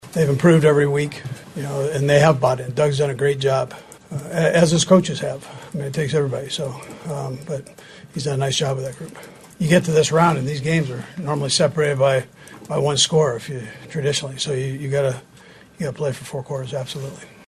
Chiefs Coach Andy Reid says they are an improved team.
1-21-andy-reid-on-jacksonville.mp3